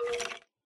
skeleton1.ogg